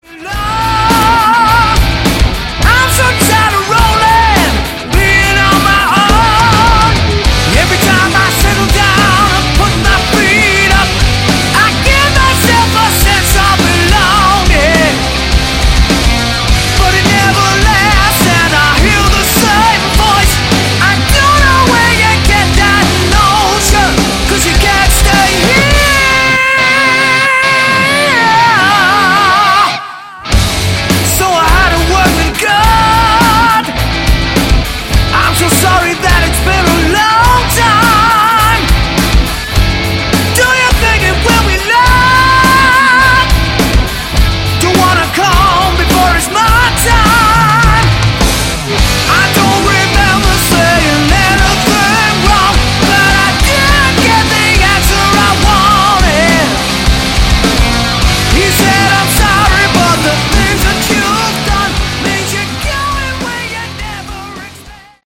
Category: Melodic Metal
guitars, bass, keyboards
vocals
drums